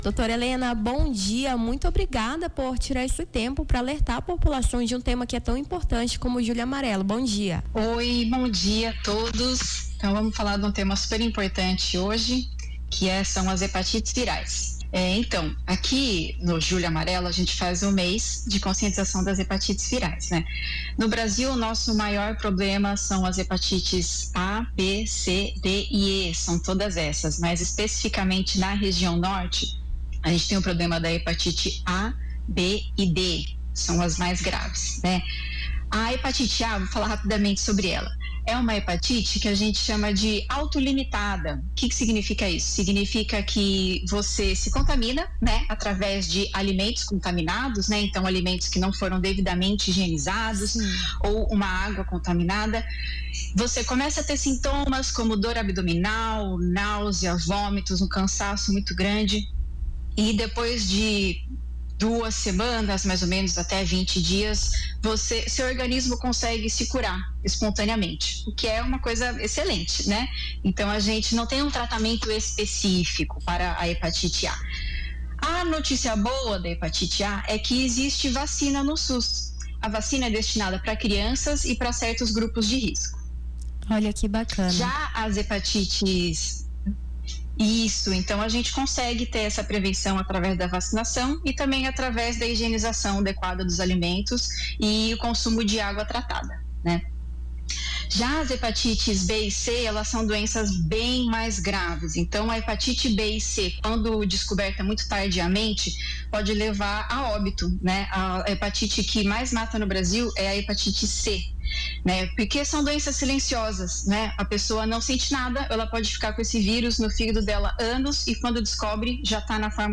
Nome do Artista - CENSURA - ENTREVISTA (JULHO AMARELO) 14-07-25.mp3